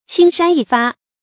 青山一發 注音： ㄑㄧㄥ ㄕㄢ ㄧ ㄈㄚˋ 讀音讀法： 意思解釋： 青山遠望，其輪廓僅如發絲一樣。